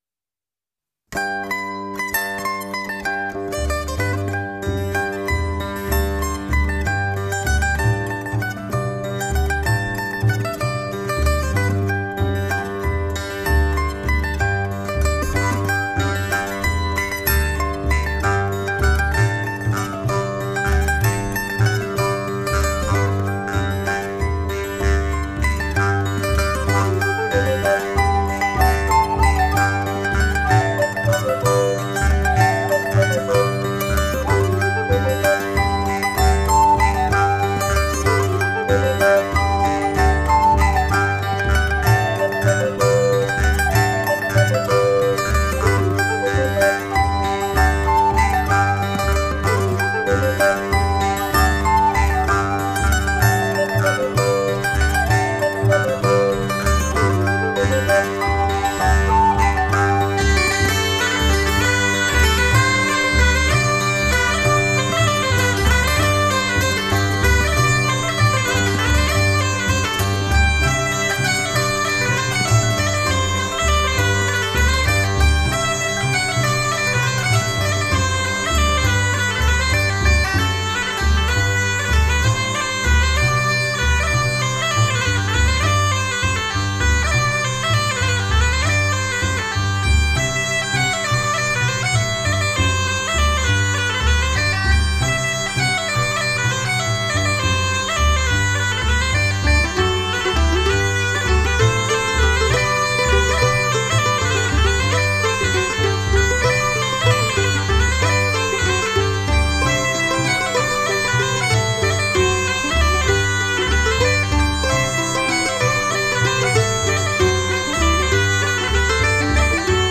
une valse suivie d'une impaire 2'31